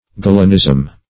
Galenism \Ga"len*ism\, n.